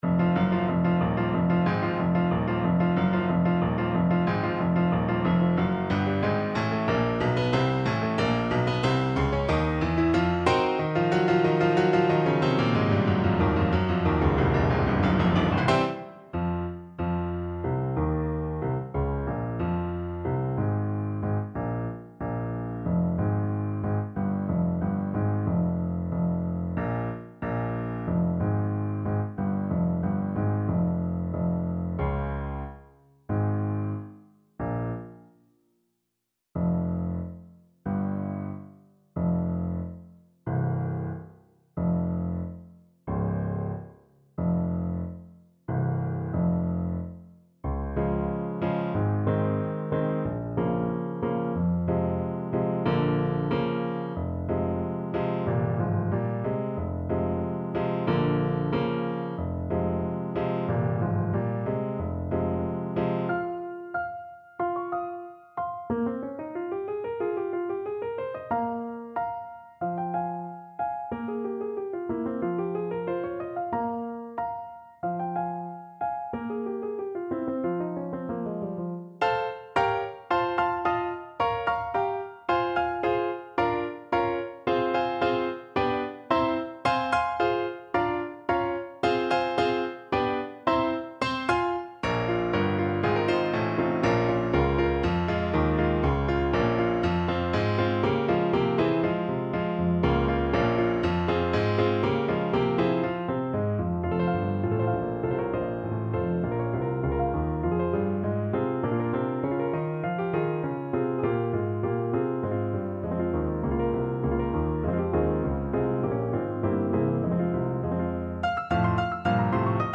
Allegro =92 (View more music marked Allegro)
Classical (View more Classical Saxophone Music)